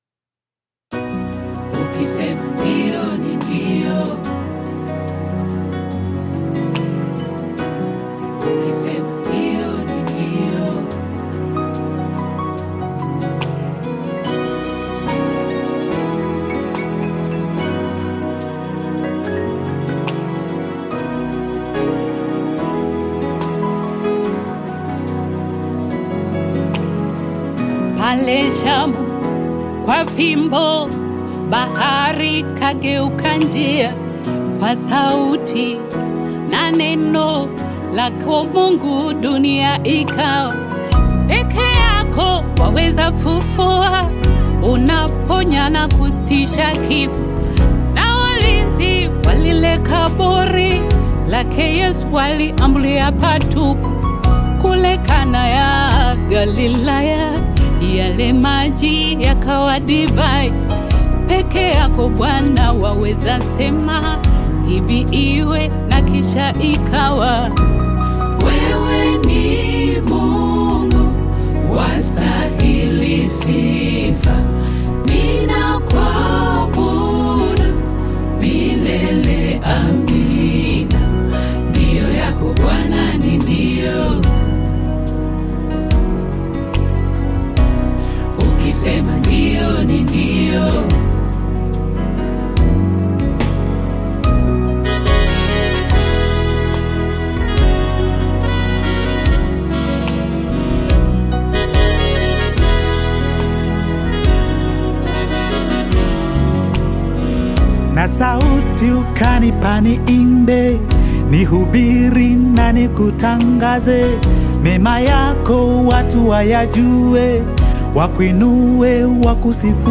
Tanzanian Gospel choir